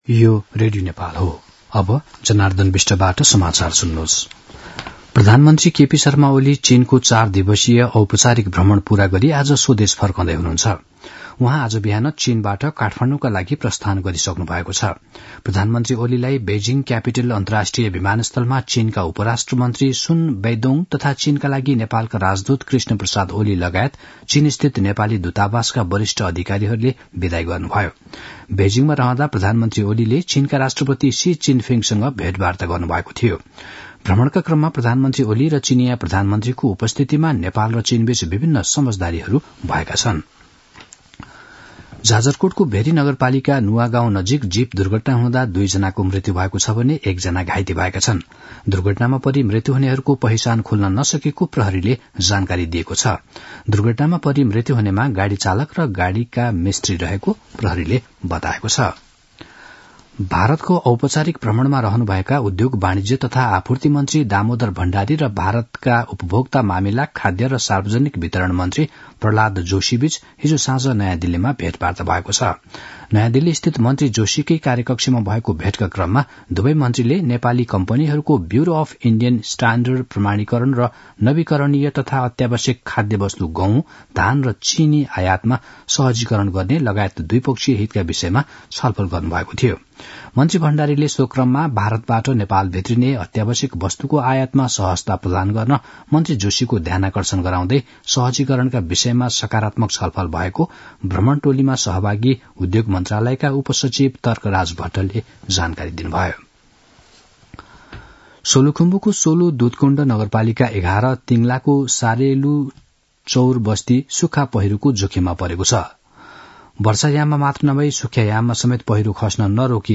मध्यान्ह १२ बजेको नेपाली समाचार : २१ मंसिर , २०८१
12-am-nepali-news-1-3.mp3